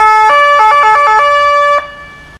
new sirens